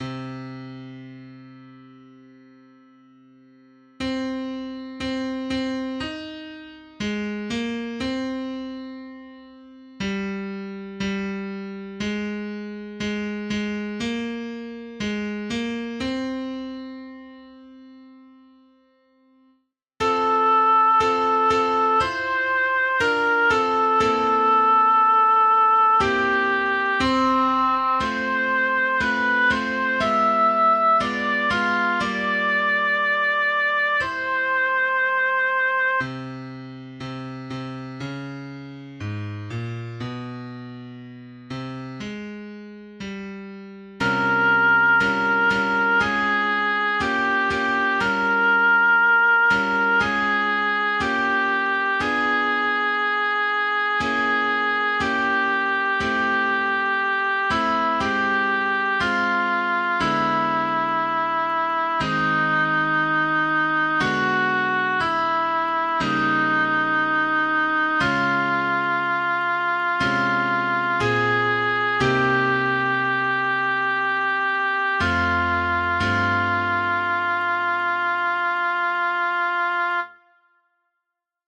Sopran 1
stemning-alfven-sopran1.mp3